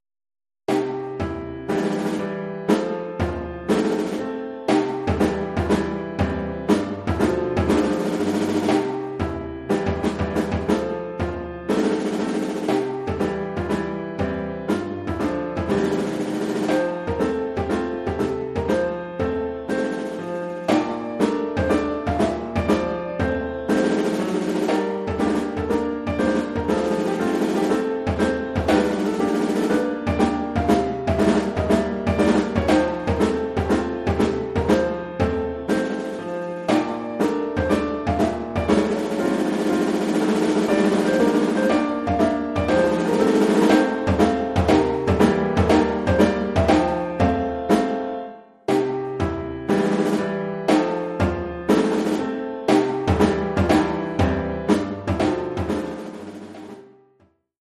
1 titre, tambour et piano : conducteur et partie de tambour
Oeuvre pour tambour et piano.
Oeuvre pour tambour avec
accompagnement de piano.